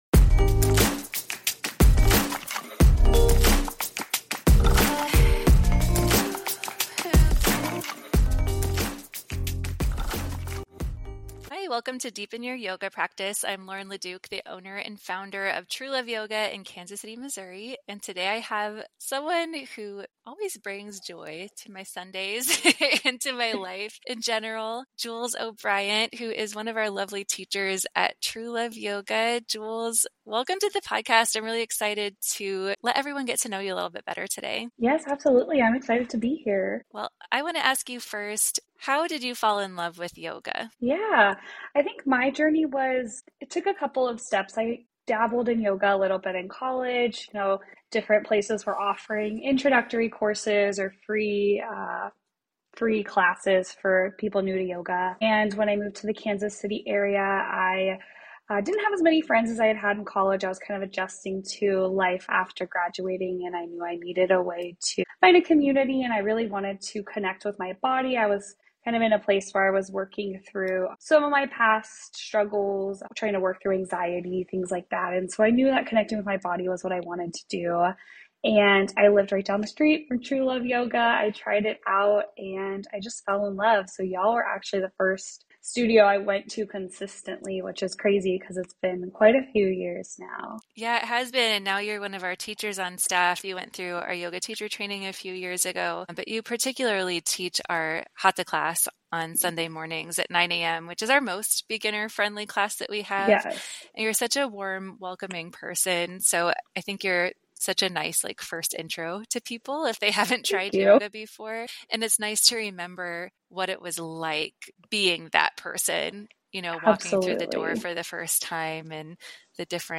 Yoga for Beginners & Beyond: A Conversation